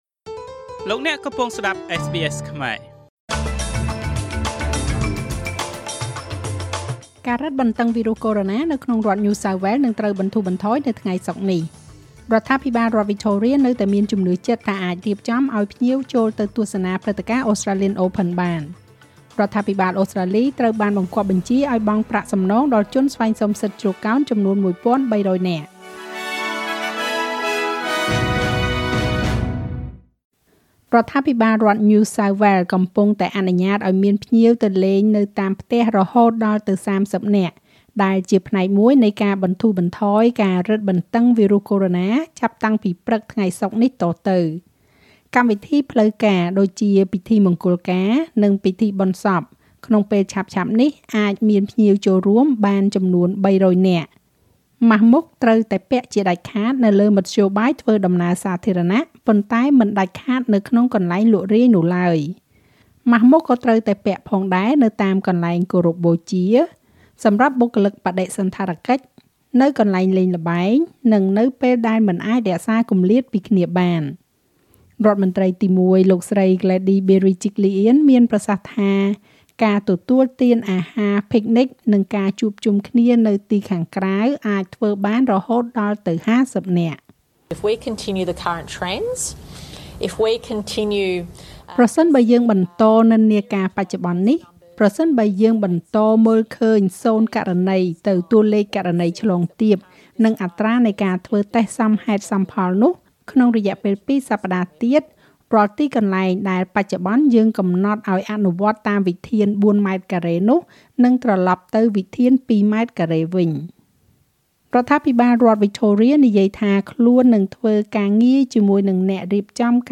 នាទីព័ត៌មានរបស់SBSខ្មែរ សម្រាប់ថ្ងៃពុធ ទី២៧ ខែមករា ឆ្នាំ២០២១។